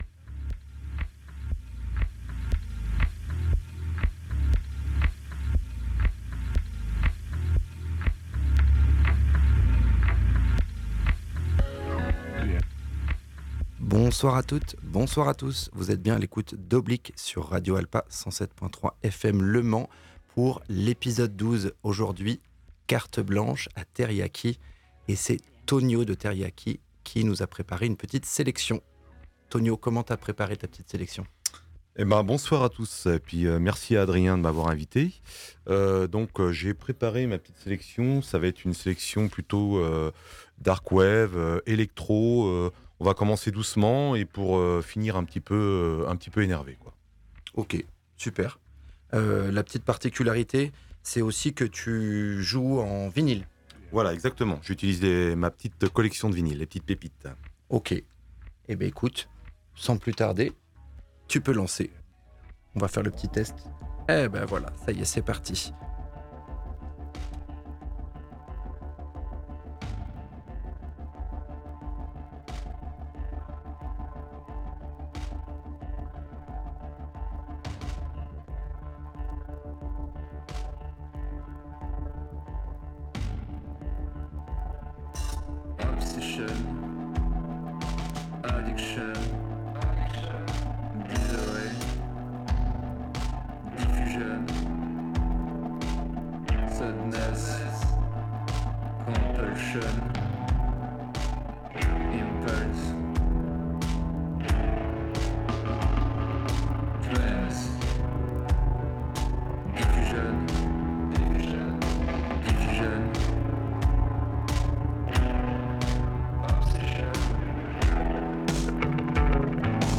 ELECTRONICA INTERVIEW